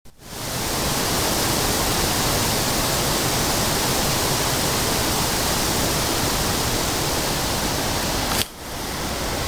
Прекрасно прошёл "Осенний полевой день".
Ещё немного аудио, в том числе запись шума ветра, романтика всё- таки, как никак!
QRM_veter.mp3